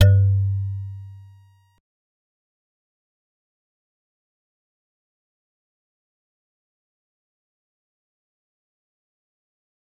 G_Musicbox-G2-pp.wav